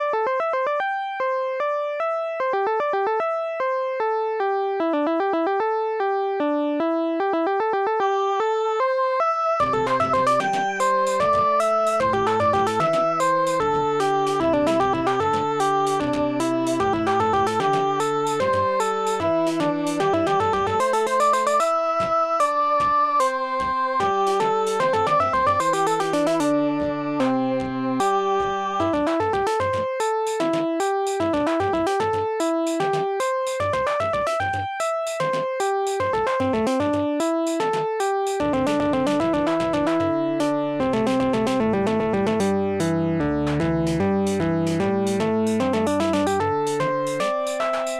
Main theme of the game, does its job and is kind of catchy.